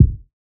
OZ - Kick 2.wav